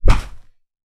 Punching Bag Rhythmic D.wav